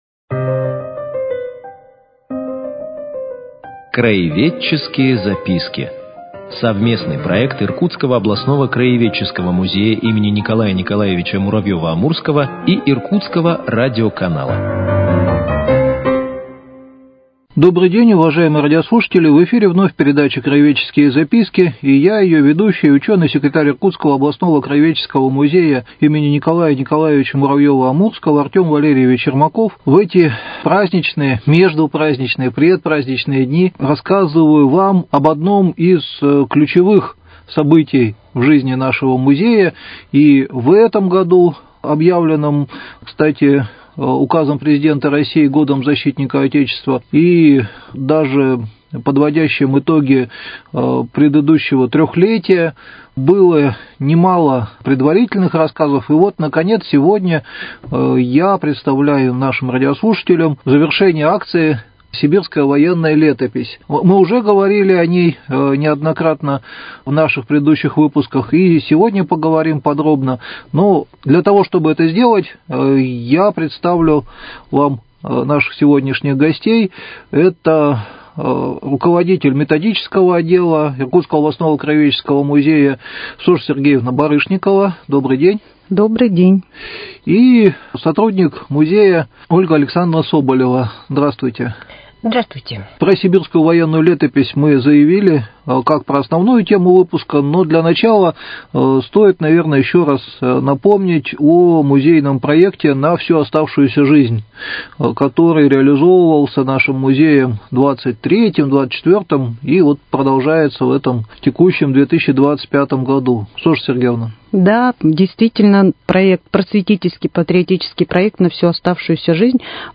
Мы предлагаем вниманию слушателей цикл передач – совместный проект Иркутского радиоканала и Иркутского областного краеведческого музея.